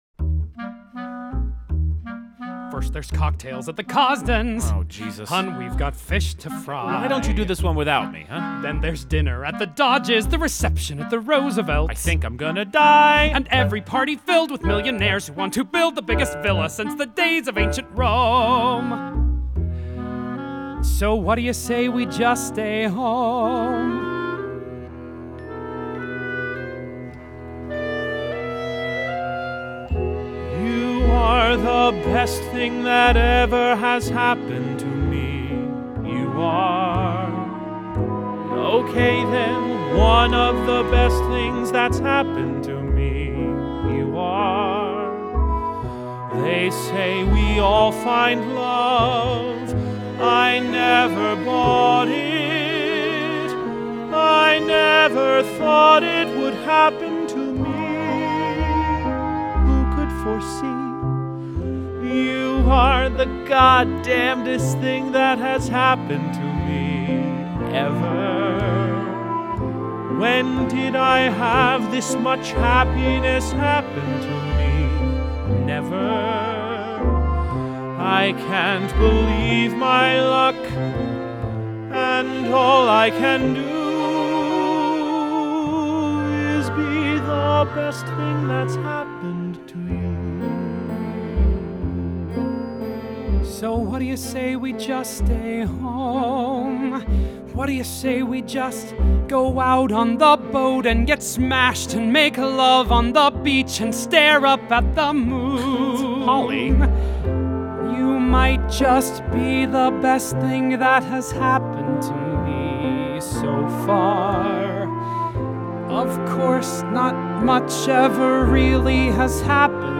2003   Genre: Musical   Artist